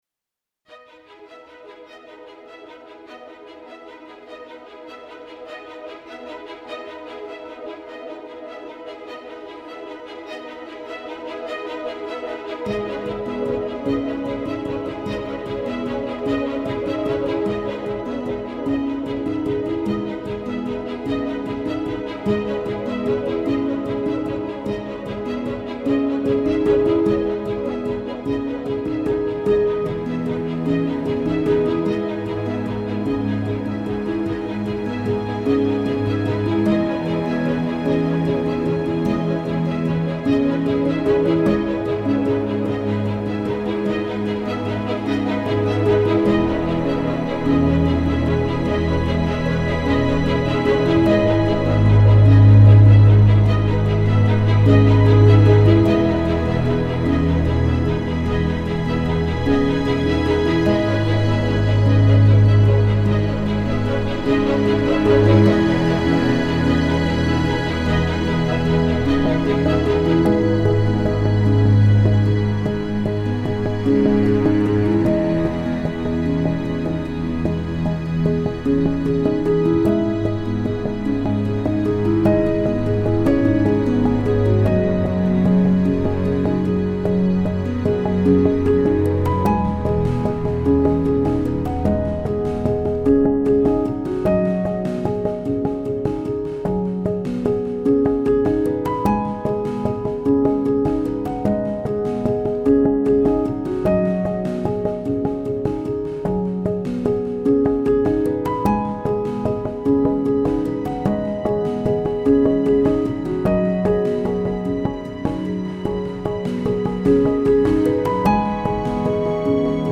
Ambient/Orchestral